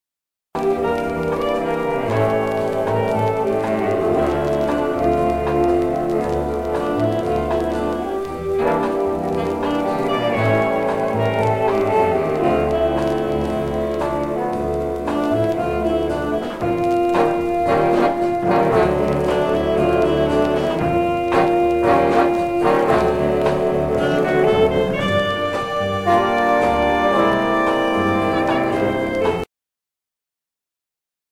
January 12-14, 1967 marked its 17th concert.
duet on saxophone
Jazz -- 1961-1970; Jazz vocals;